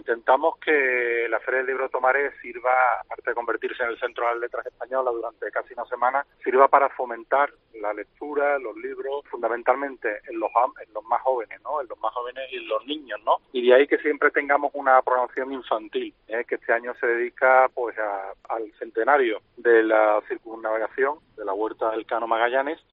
José Luis Sanz comenta la programación infantil de la Feria del Libro de Tomares